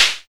NOISE CLAP.wav